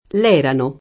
ella [%lla] pron.; pl.